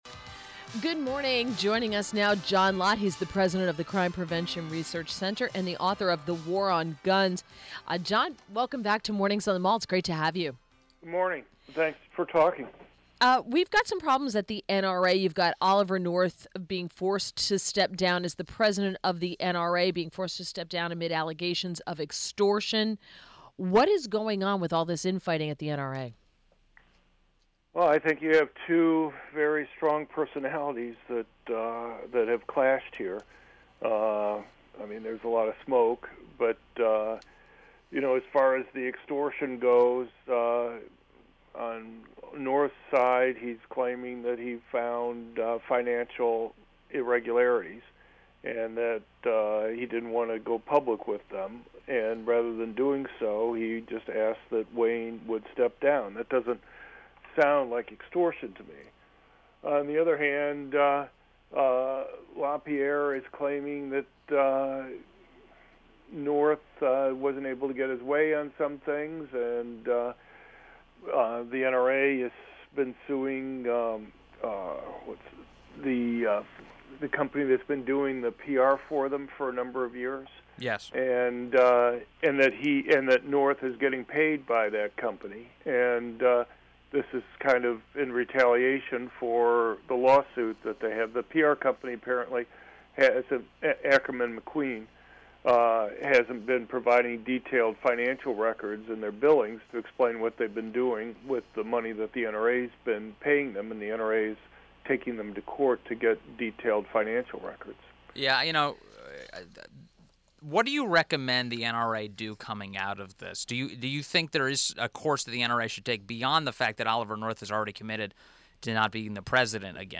media appearance radio